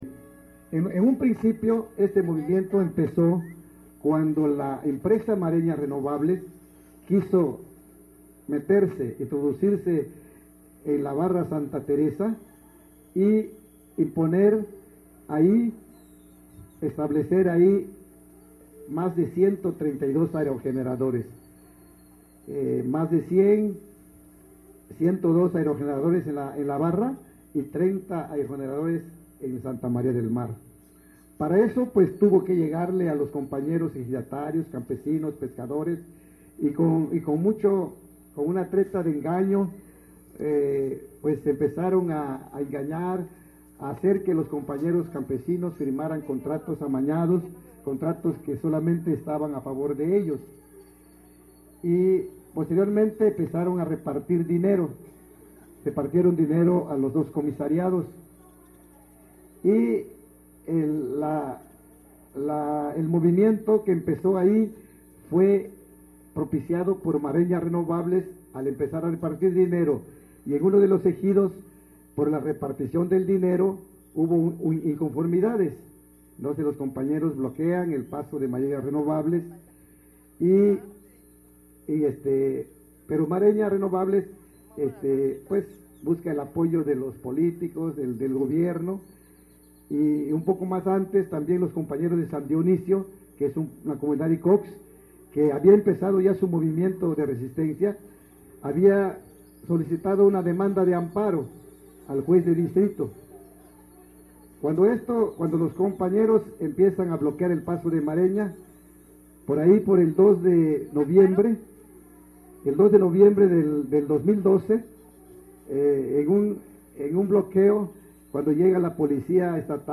A continuación te invitamos a escuchar algunos audios de pobladores de esta comunidad y ver algunas imágenes de este territorio